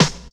WAV snaredrums